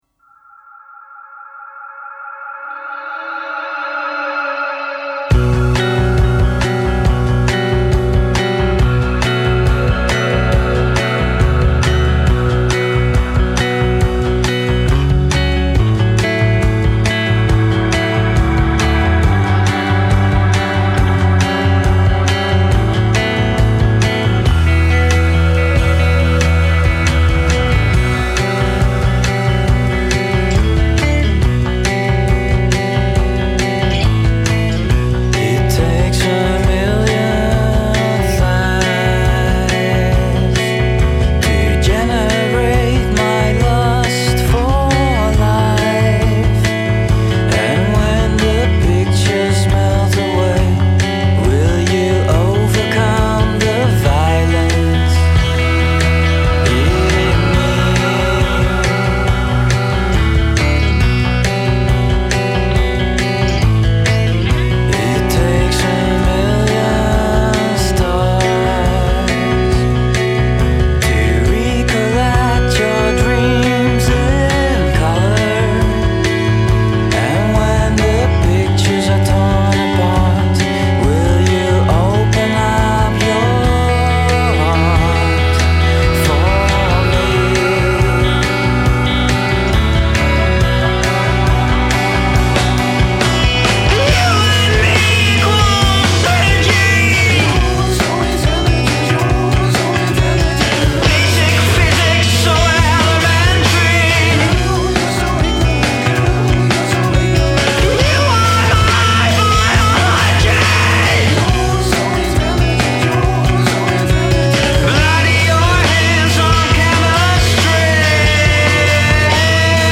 lekker dreinende